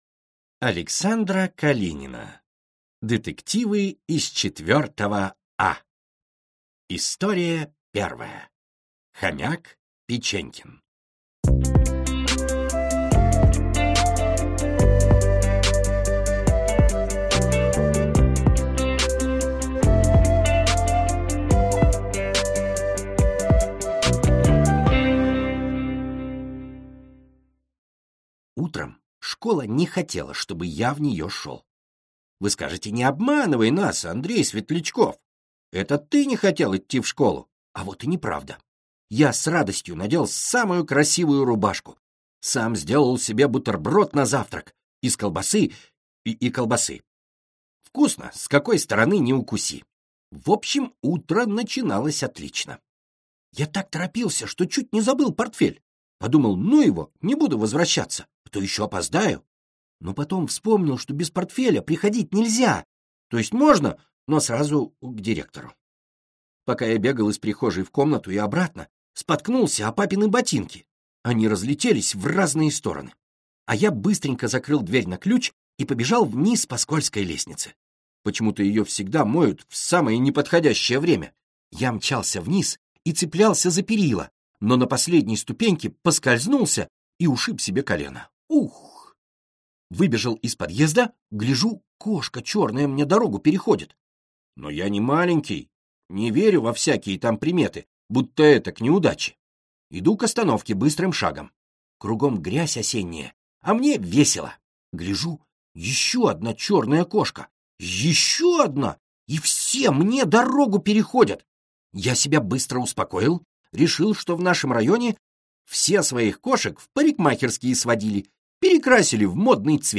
Аудиокнига Детективы из 4«А» | Библиотека аудиокниг
Прослушать и бесплатно скачать фрагмент аудиокниги